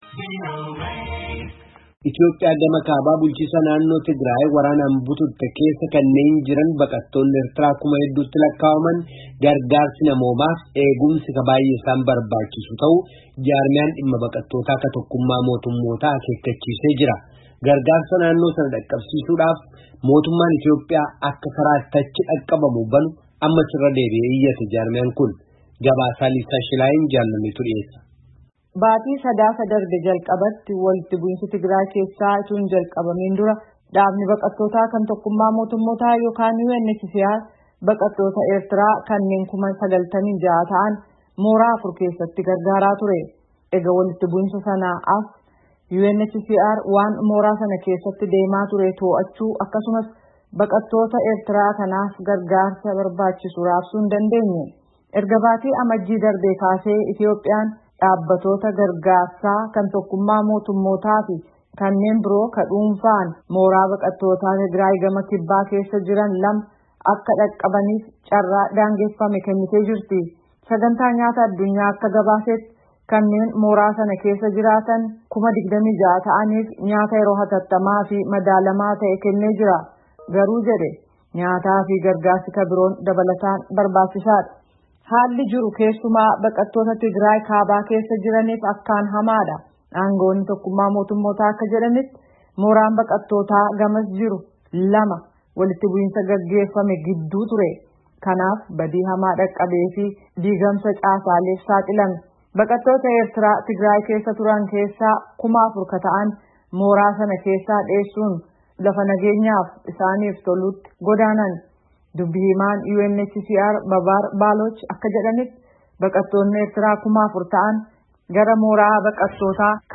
Gabaasaa